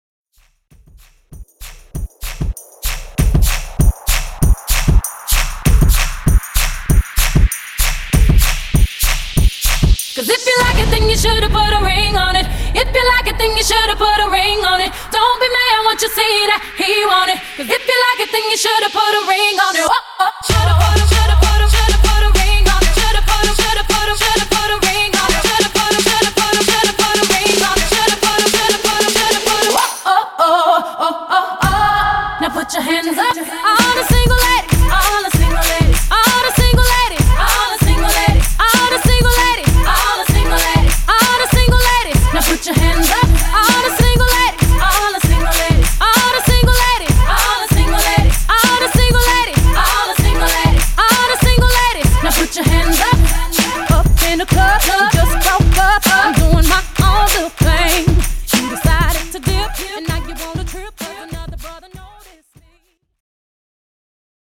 Genre: 2000's
Dirty BPM: 107 Time